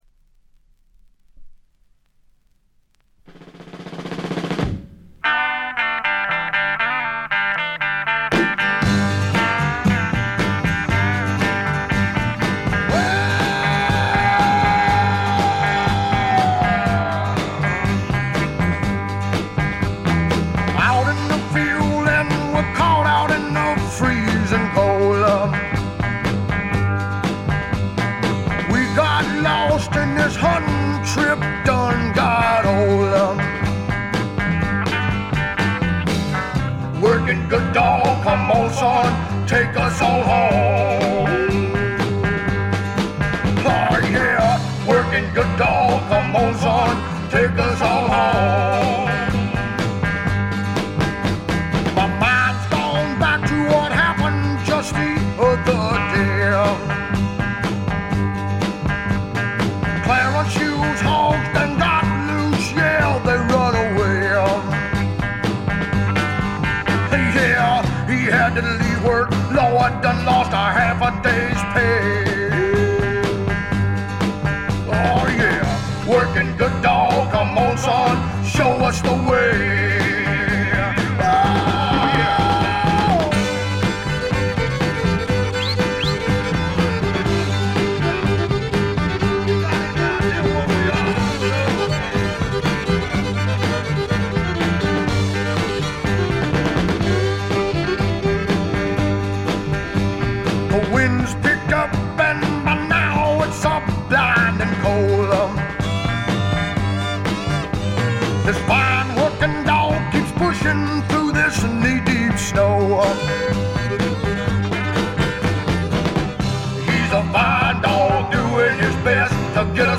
部分試聴ですが、ほとんどノイズ感無し。
肝心の音はといえば南部の湿った熱風が吹きすさぶ強烈なもの。
試聴曲は現品からの取り込み音源です。